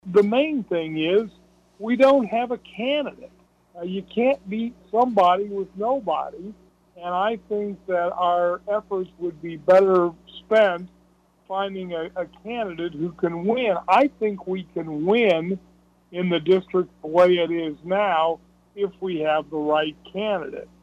Sanders is the Republican Representative for the 69th District and joined in on the KSAL Morning News Extra and says he thinks elections can be won around the state without redrawing the maps.